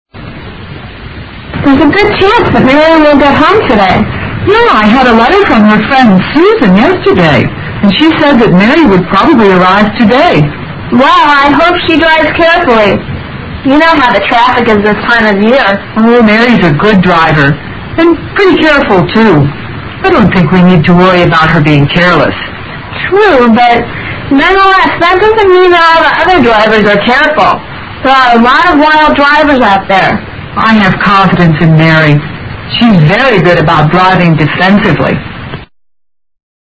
Dialogue12